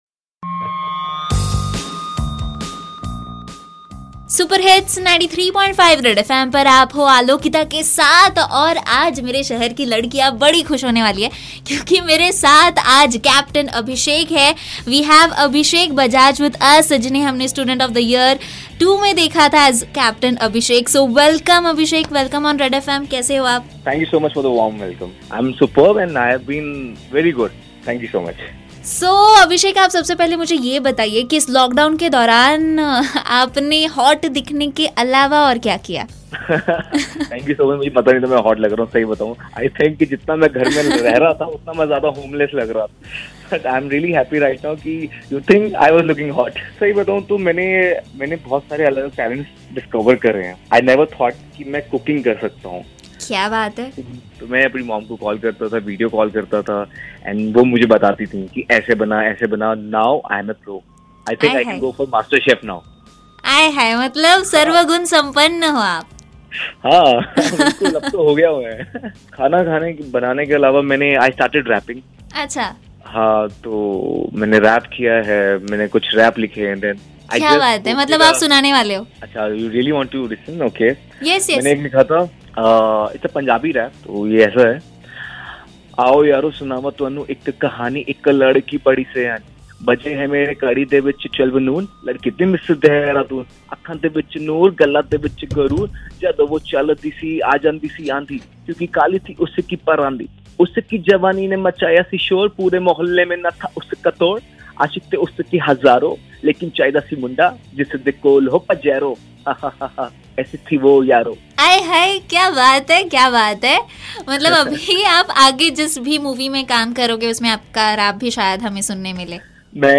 ABHISHEK BAJAJ INTERVIEW - 1